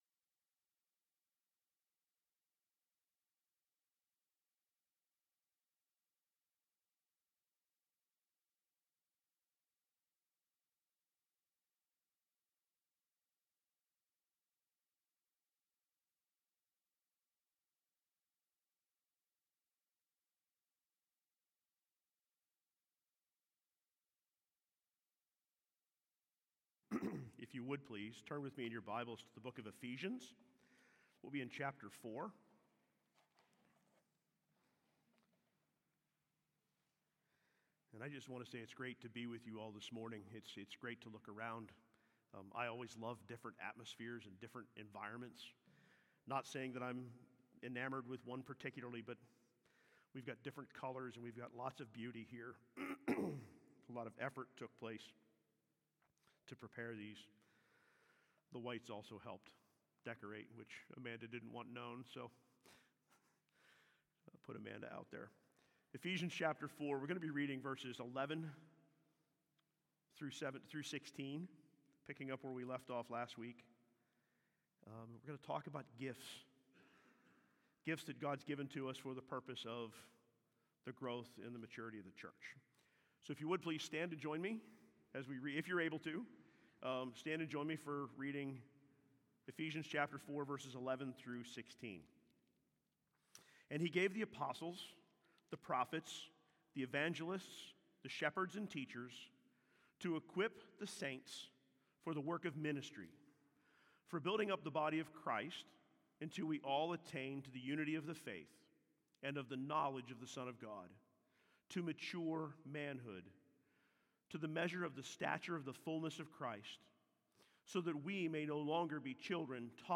SERMONS | Sunbury City Church